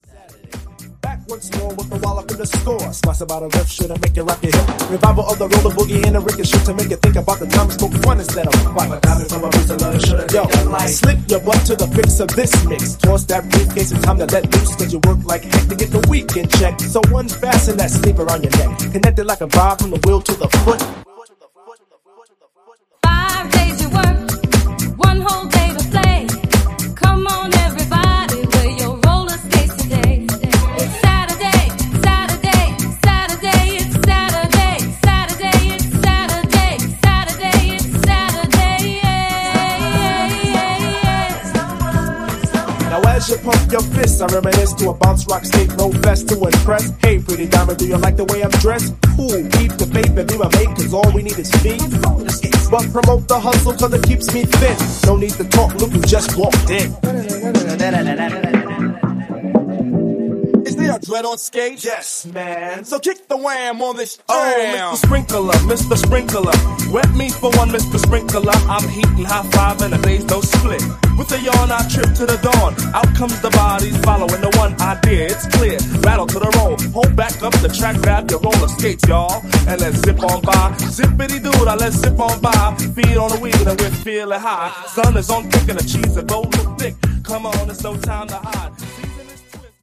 loose-limbed hip-house punchiness